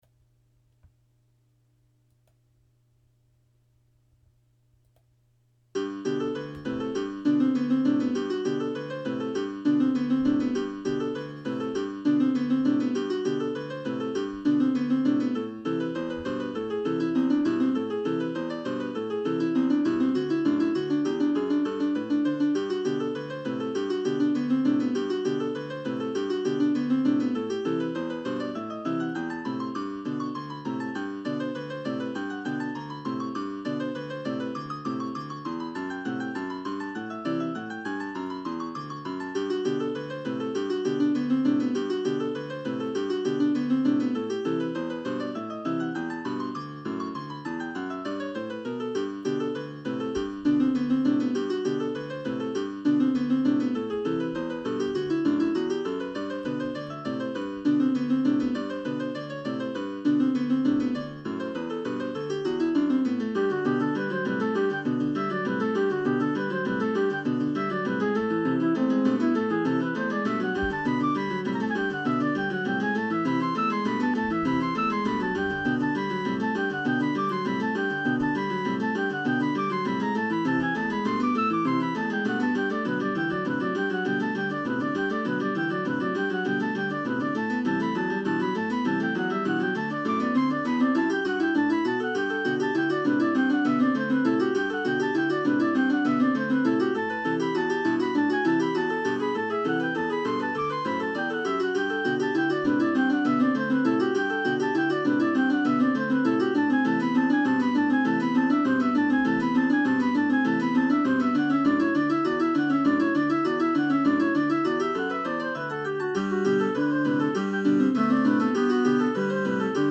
for flute, bassoon, piano, cello and sea